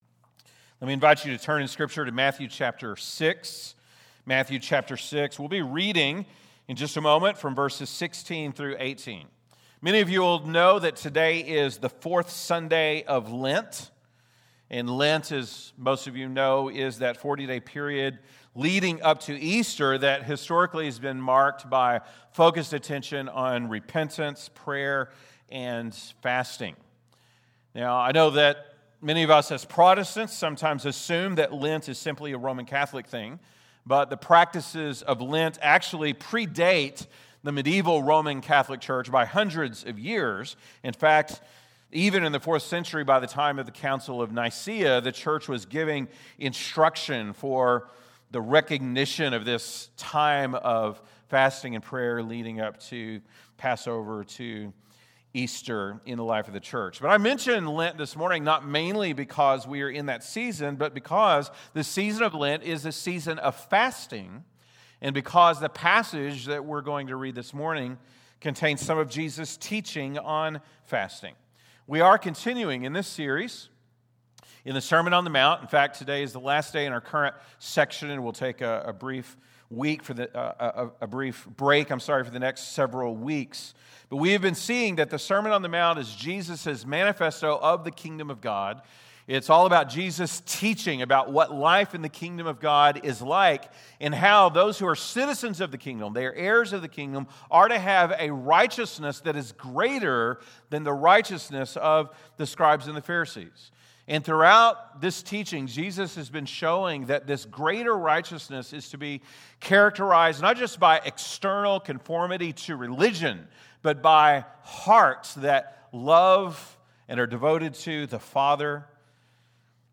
March 22, 2026 (Sunday Morning)